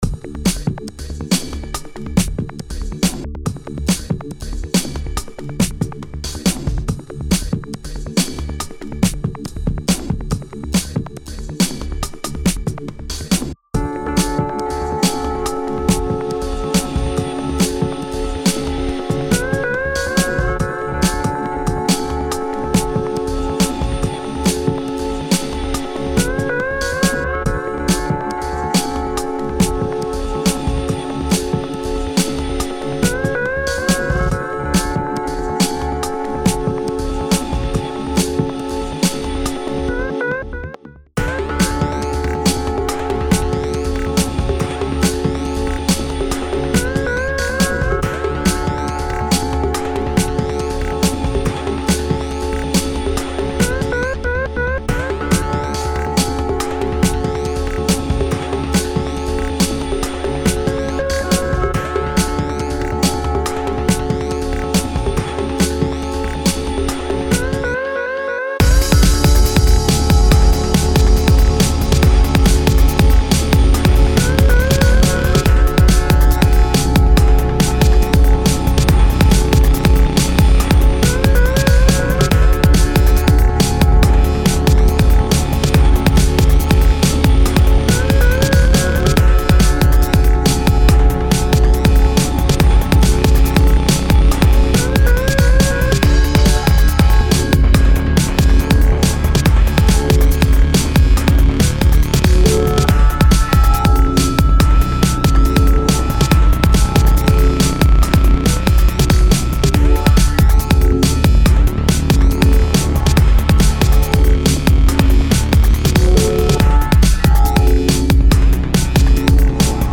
une drum'n'bass oscillant entre chaloupement
et gros son plutôt efficace